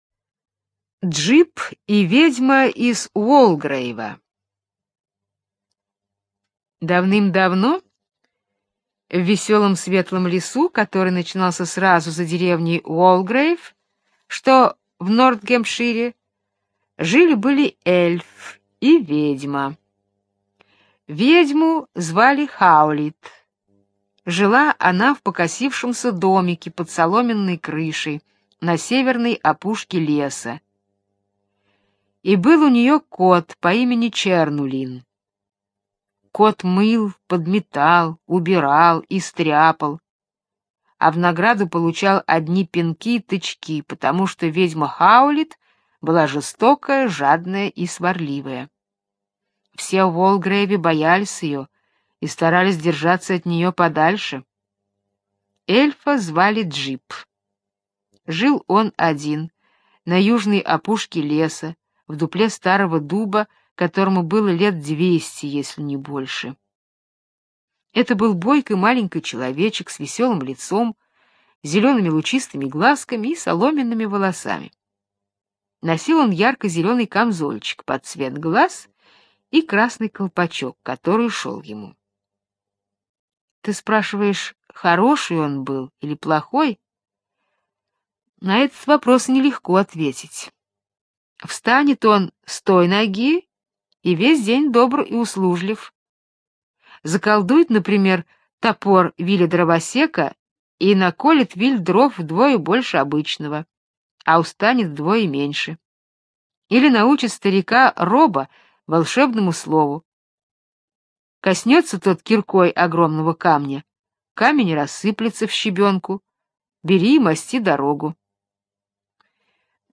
Джип и ведьма из Уолгрейва - британская аудиосказка - слушать онлайн